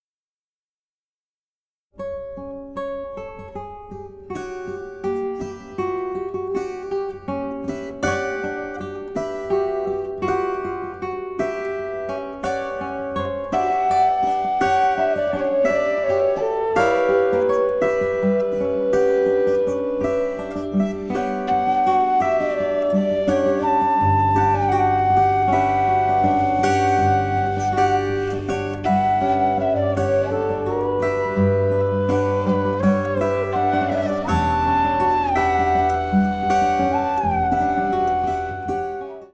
Flute, Alto flute, Bansuri flutes
16-string Classical guitar